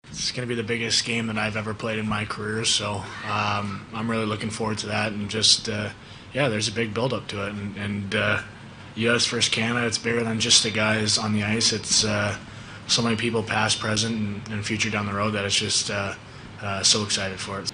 Brady Tkachuk says playing the Canadians is going to be incredible.